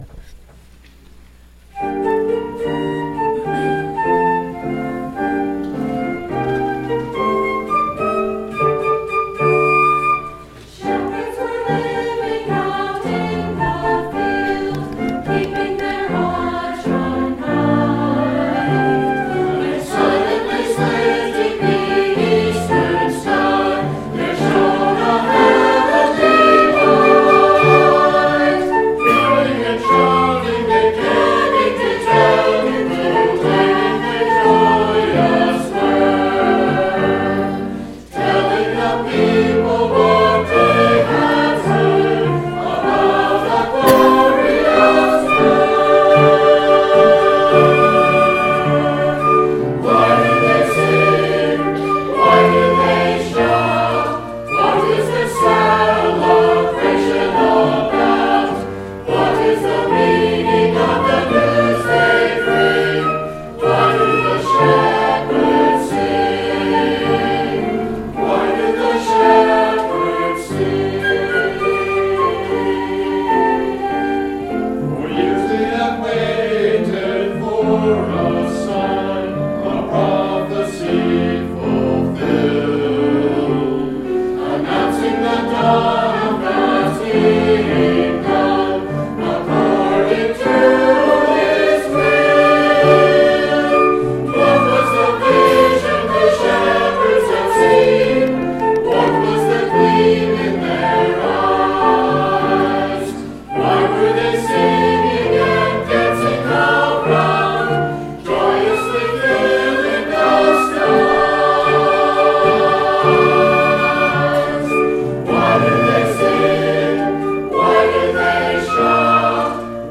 Performance Delivered at: Church of Jesus Christ of Latter Day Saints, Essex, VT
Here are two choral pieces sung by the Choir of the United Church of Underhill